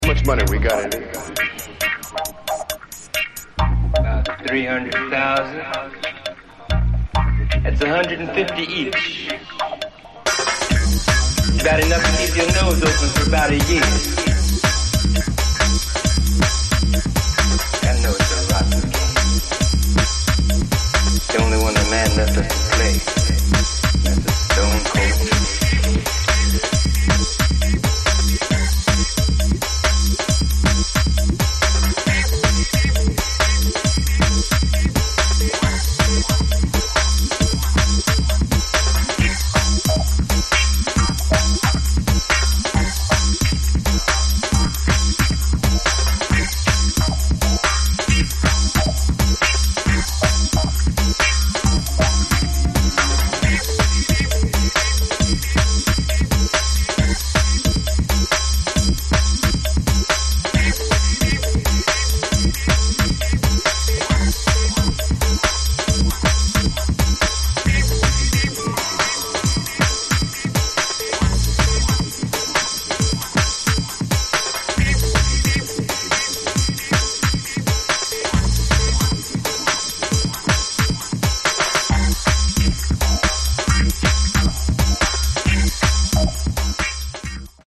A Dub-Step-Breaks holy grailer from 2002.